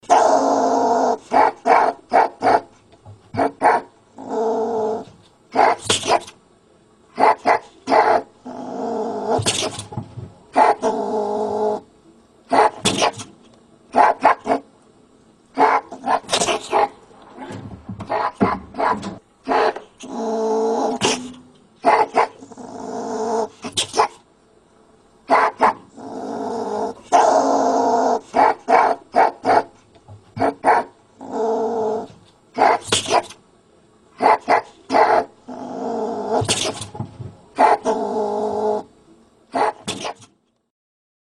Звуки соболя
На этой странице собраны разнообразные звуки, которые издают соболи – от нежного мурлыканья до резкого рычания.
Звук Соболя